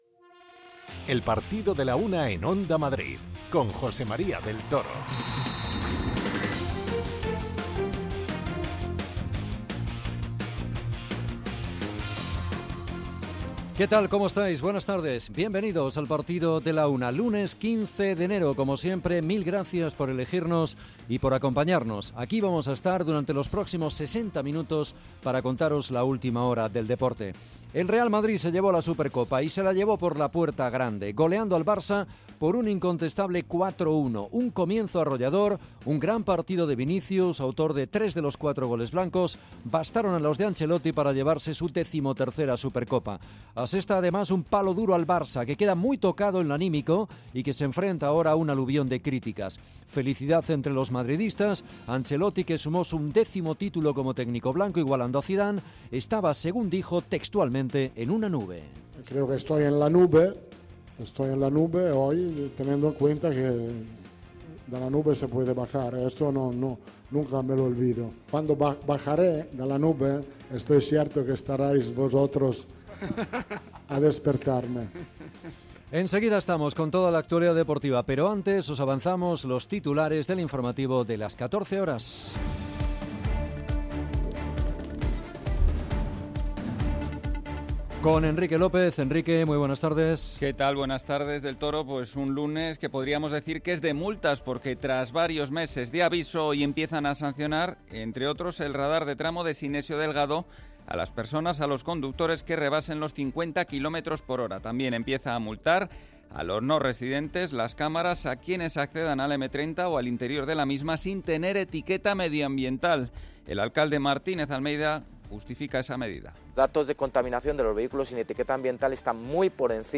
Escuchamos al propio Vinicius, a Ancelotti y a Xavi.